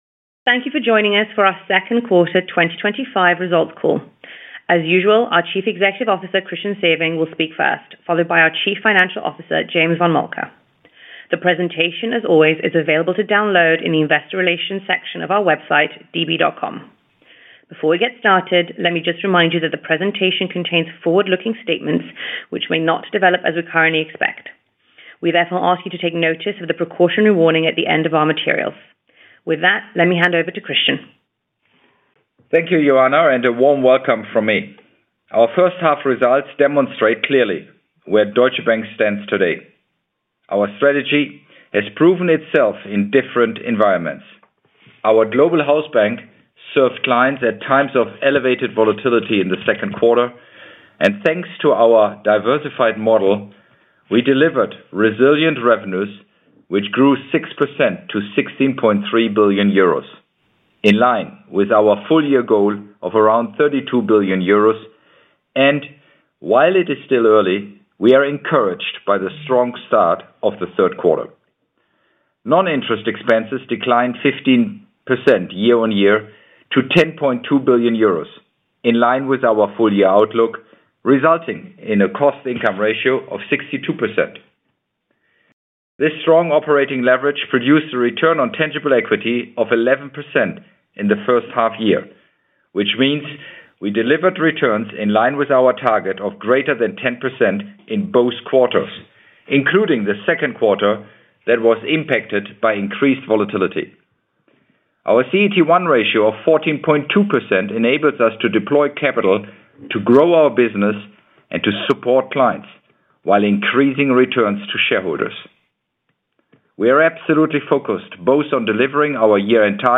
Presentation Analyst Conference Call
Deutsche-Bank-Q2-2025-Analyst-Call-Pre-Recording.mp3